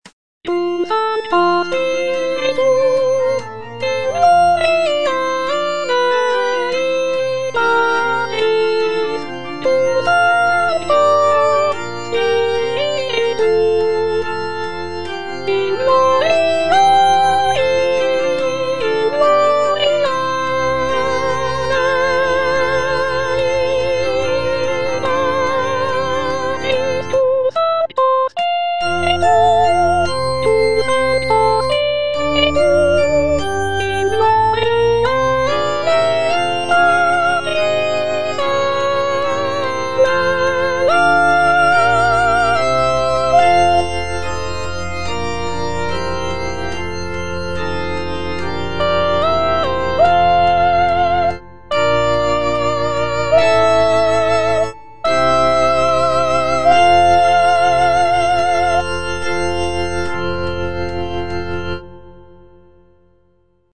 Soprano (Voice with metronome) Ads stop